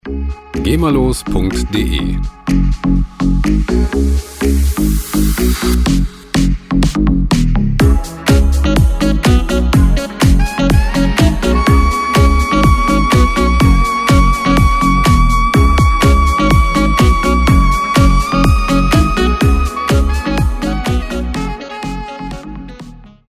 Gema-freie House Loops
Musikstil: House
Tempo: 124 bpm